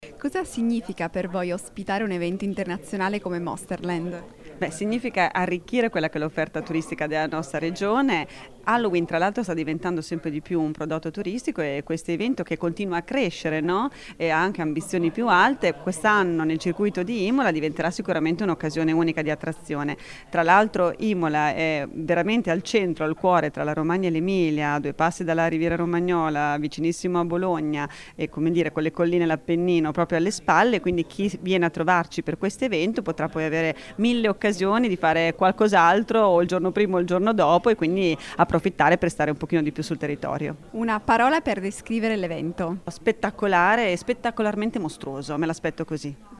Ascolta l’intervista a Roberta Frisoni, Assessora Turismo, Commercio e Sport Regione Emilia Romagna:
Roberta-Frisoni-Assessora-Turismo-Commercio-e-Sport-Regione-Emilia-Romagna.mp3